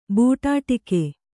♪ būṭāṭike